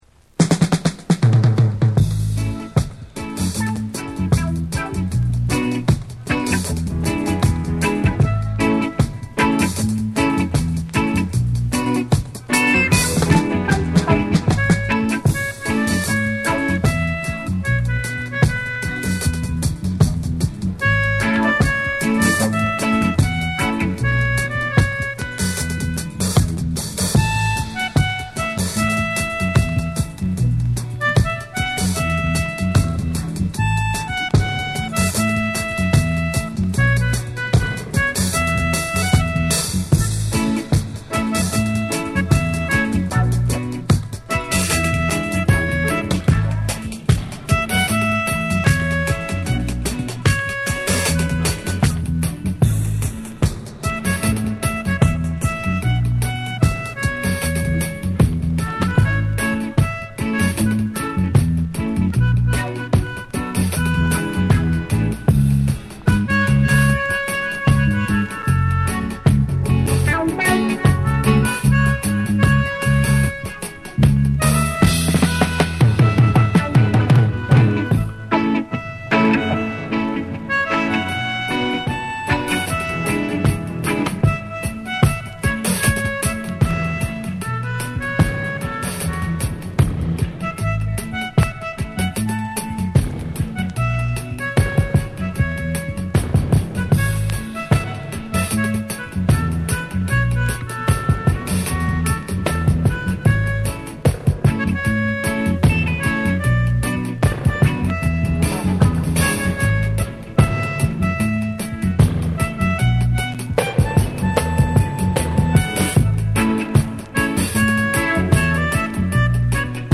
彼ならではの浮遊感あるメロディカが、幻想的に響き渡るスピリチュアルなダブの世界。